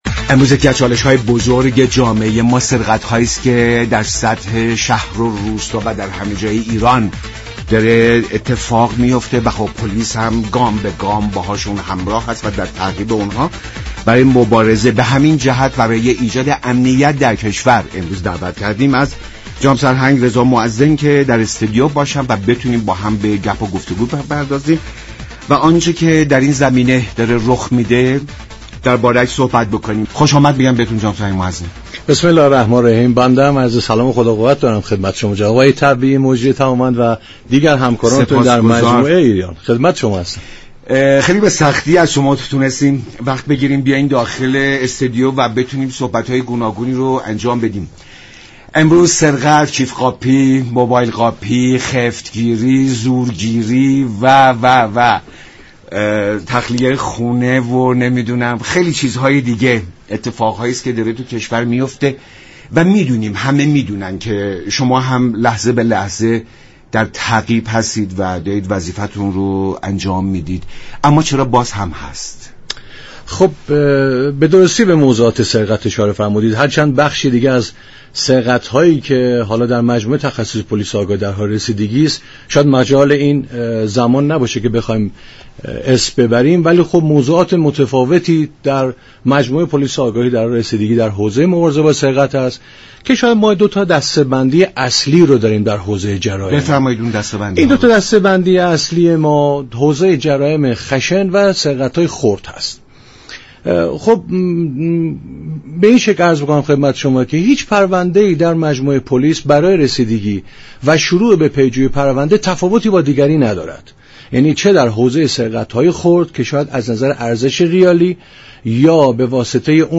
معاون مبارزه با سرقت پلیس آگاهی ناجا در برنامه ایران امروز گفت: پلیس تخصصی آگاهی شبانه روز و بدون هیچ تعطیلی مشغول مقابله با جرم است.